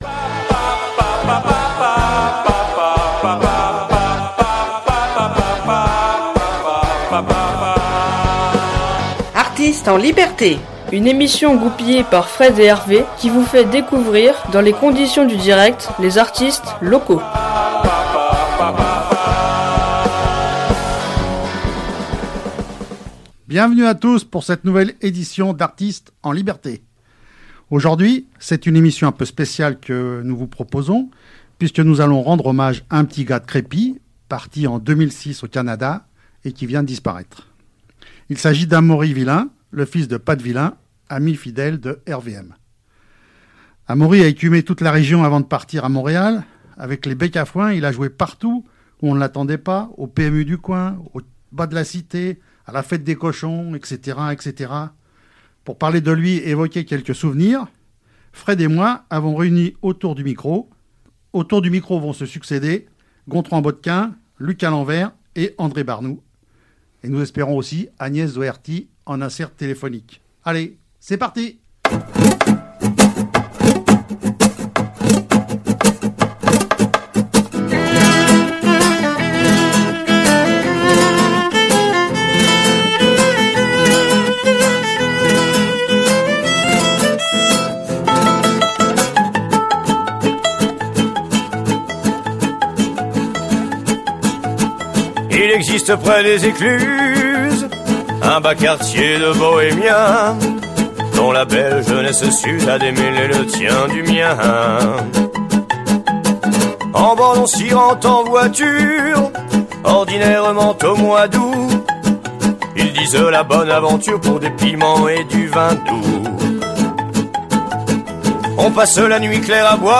Artistes en liberté : une émission diffusée ce lundi 28 décembre, à 21h.